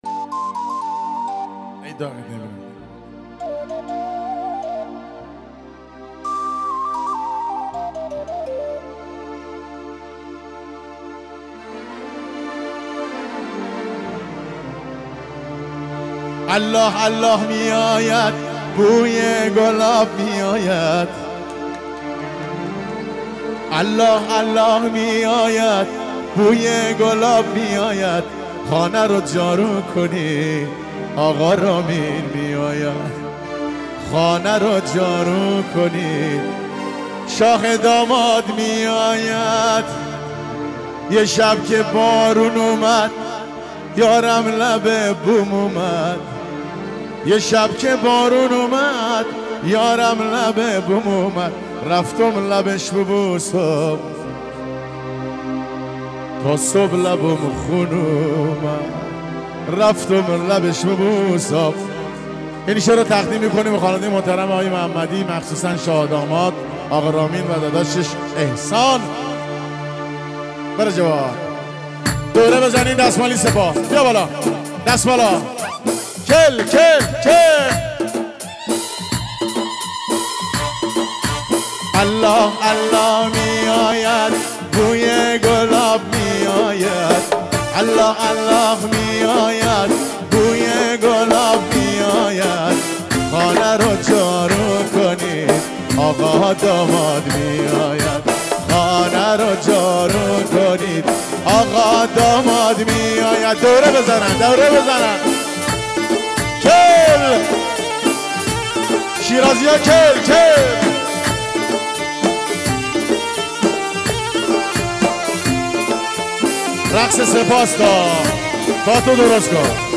یکی از آثار زیبا و شاد